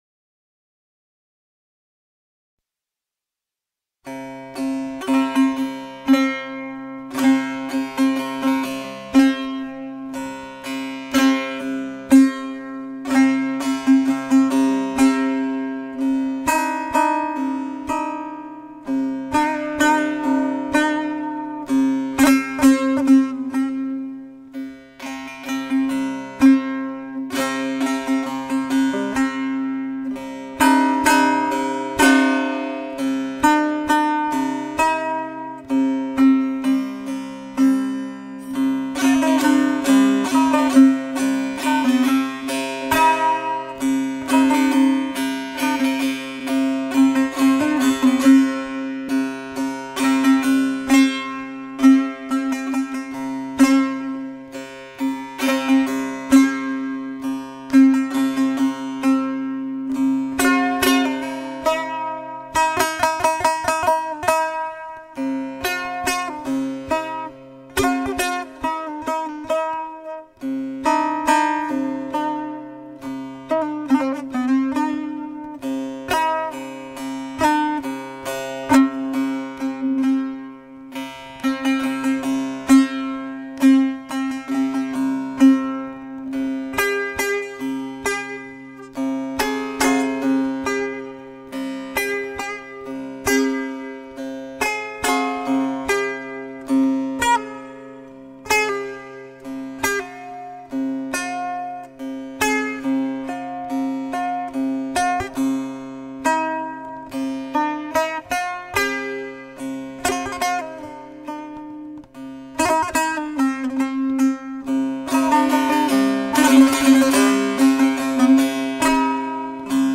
نی
دف
کردی